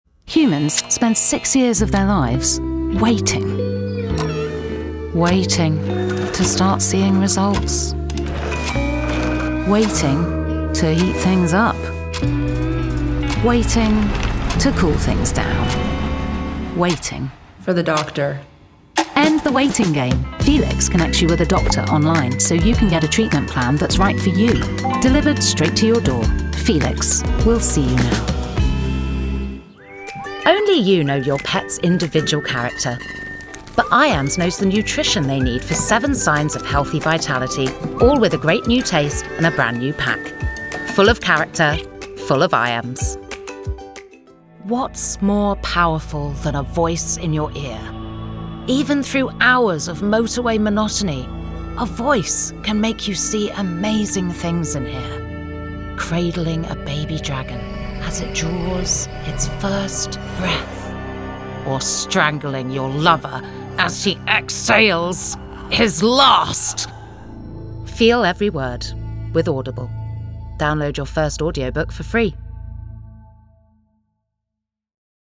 Native Accent: RP Characteristics: Comical and Quirky